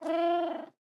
Minecraft Version Minecraft Version latest Latest Release | Latest Snapshot latest / assets / minecraft / sounds / mob / cat / ocelot / idle1.ogg Compare With Compare With Latest Release | Latest Snapshot